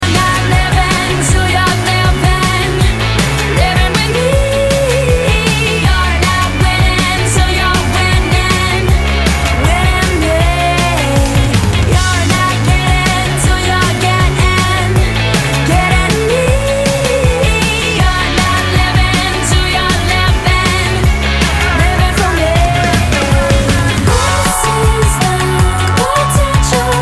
• Качество: 128, Stereo
громкие
женский вокал
Pop Rock